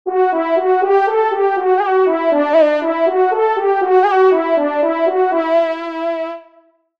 FANFARE
Auteur : Anonyme
Pupitre de Chant